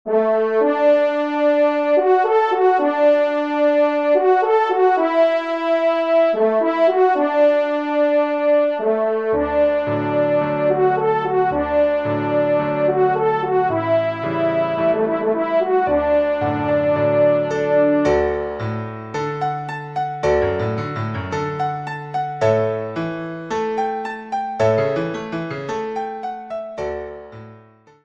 Pupitre Trompe ou Cor